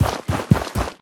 biter-walk-big-2.ogg